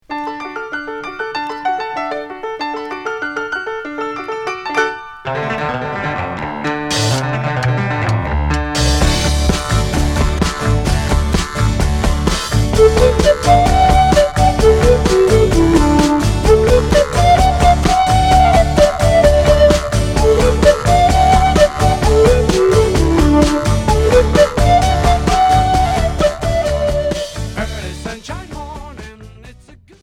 Progressif Deuxième 45t retour à l'accueil